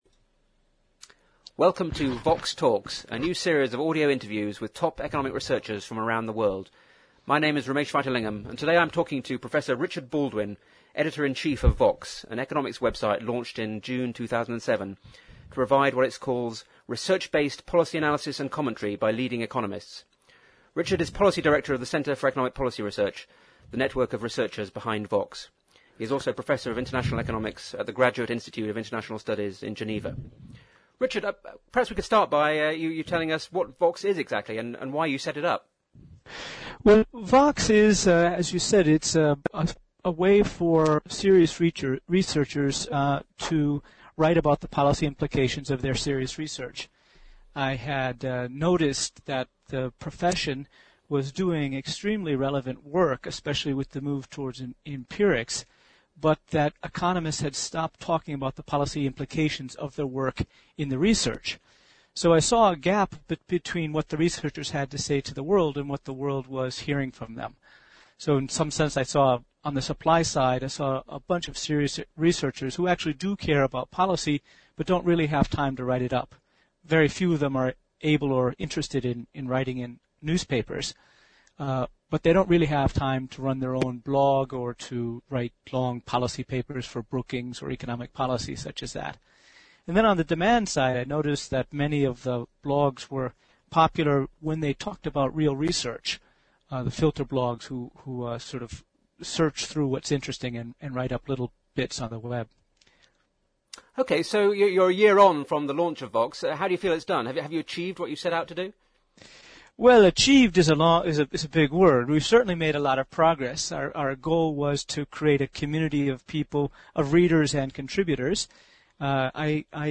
In this inaugural Vox Talks audio interview